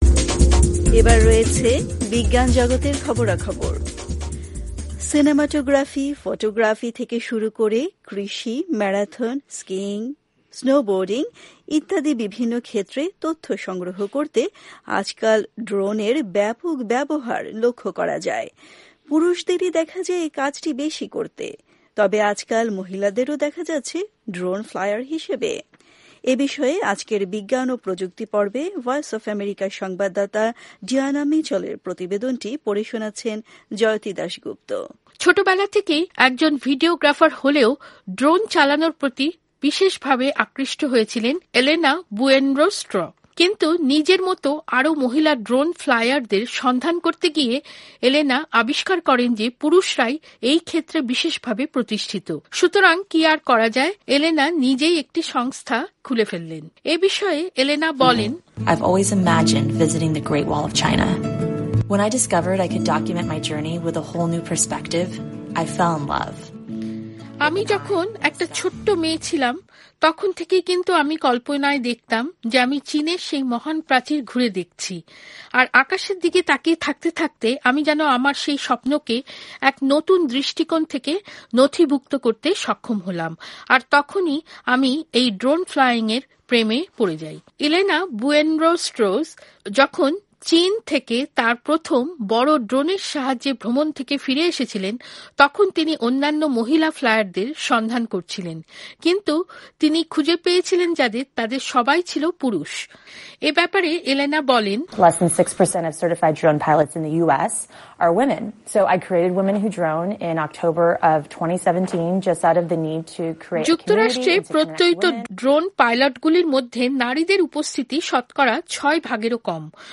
আজকের বিজ্ঞান ও প্রযুক্তি পর্বে